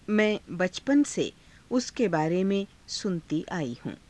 ふつう